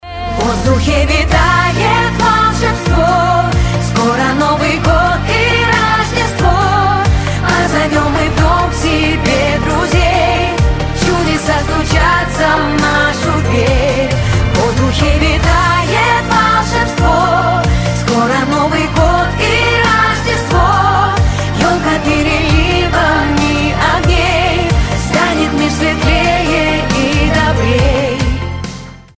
поп
колокольчики
новогодние